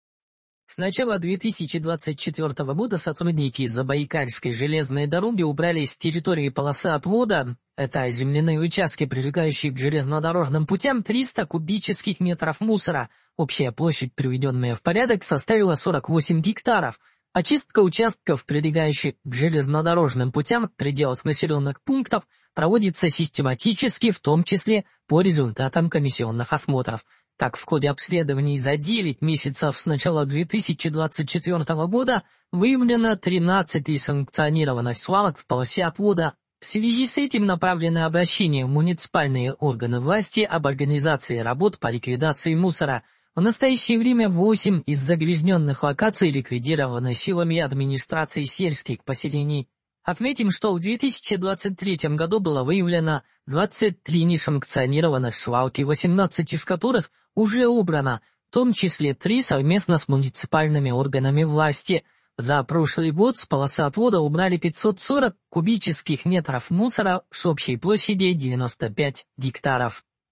Аудиовариант новости !